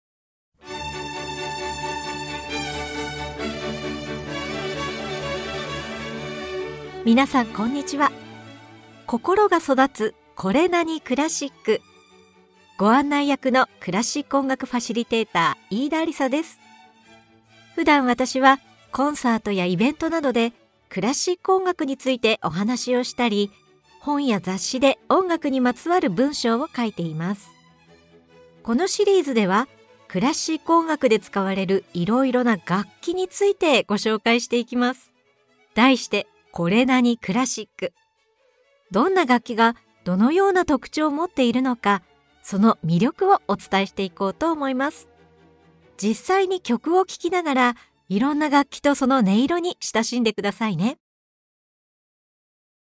実際に曲を聴きながら、いろんな楽器とその音色に親しんでください。